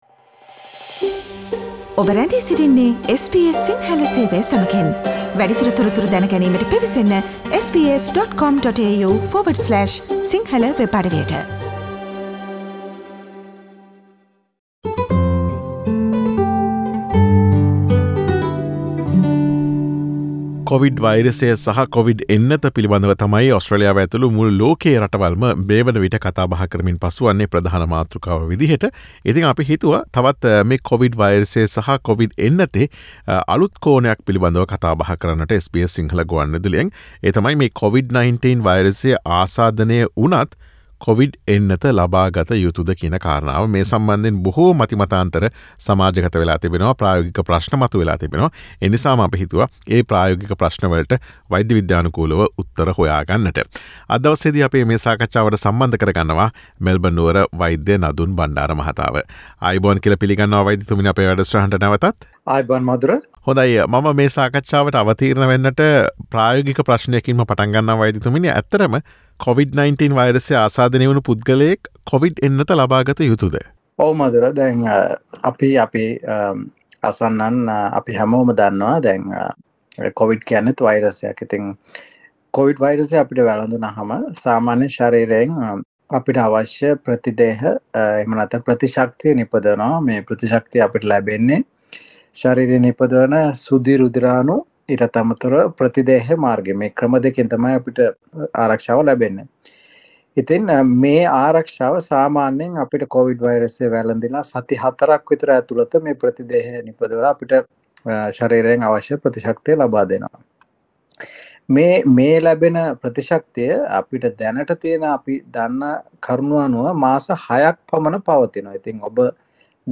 කොවිඩ් වයිරසය ආසාදනය වී සුවවූ පුද්ගලයින් කොවිඩ් එන්නත ලබා ගත යුතුද සහ ඊට නියමිත කාලයක් තිබේද යන්න පිළිබඳ SBS සිංහල ගුවන් විදුලිය සිදුකළ සාකච්ඡාවට සවන් දෙන්න.